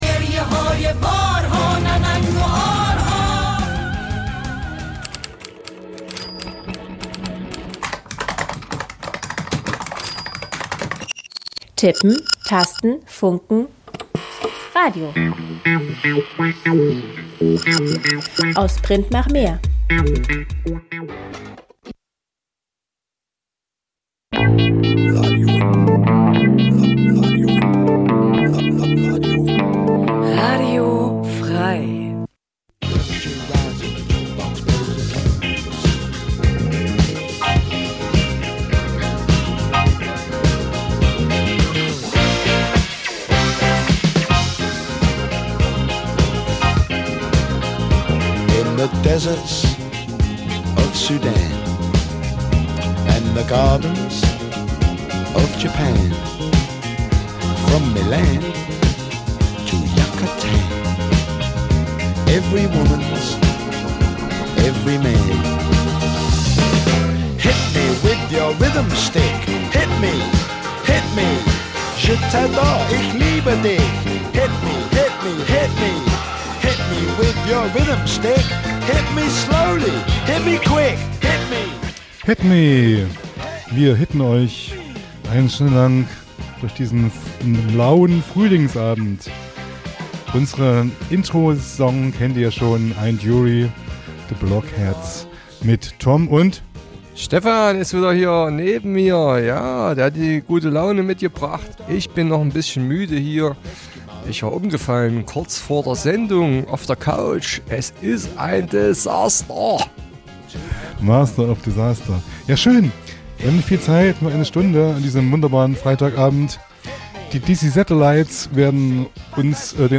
Zwei Musikfreunde duellieren sich mit raren Rock- und Punklegenden... - vinyl meets mp3... crossover zwischen den Welten, Urlaubsmusik und Undergroundperlen.
Einmal im Monat Freitag 21-22 Uhr Live on Air und in der Wiederholung montags drauf 12 Uhr.